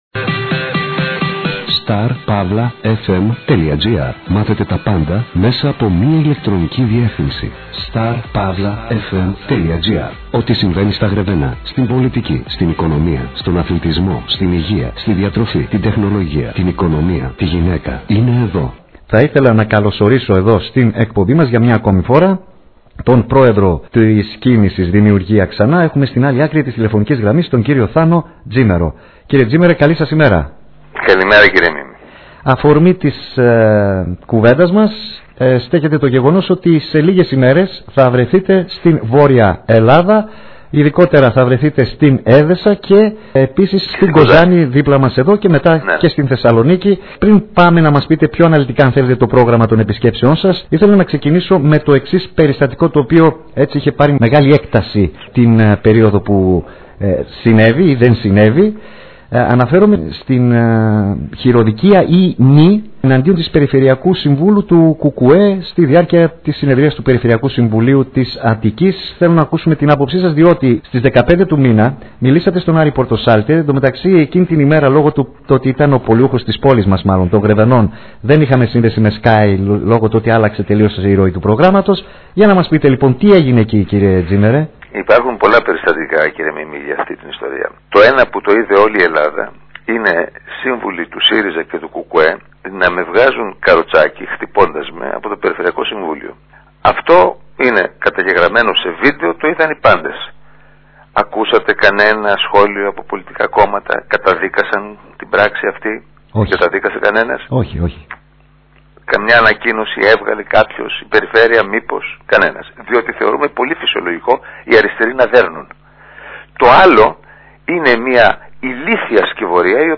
Στον Star-fm μίλησε ο ο Πρόεδρος τής “Δημιουργία Ξανά!” Θάνος Τζήμερος, με αφορμή την επίσκεψή του στην Μακεδονία.